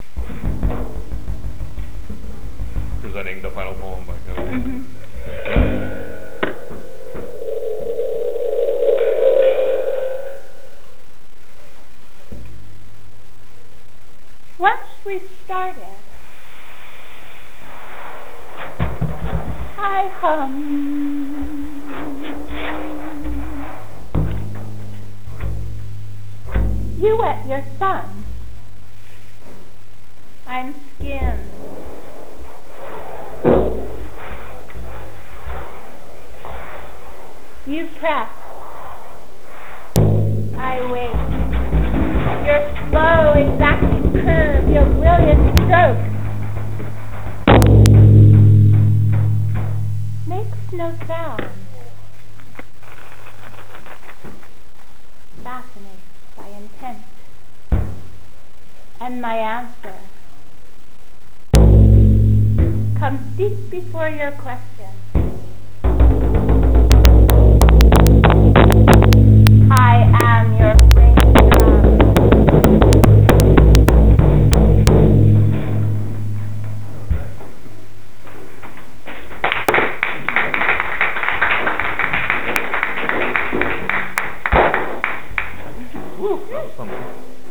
Performance of Poems
Monday Night Poetry, November 12, 2007
The underside has hundreds of small ringlike jingles.
We also used paintbrushes to sound the instruments, as an extended technique, and lotus pods to make soft rattling sounds. The initial "roar" in the piece was made by a "spring drum", a tubular shaped drum with a long spring dangling that is shaken for a "stormy" effect.